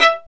Index of /90_sSampleCDs/Roland L-CD702/VOL-1/STR_Violin 1-3vb/STR_Vln1 % marc